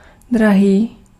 Ääntäminen
IPA : /ɪkˈspɛnsɪv/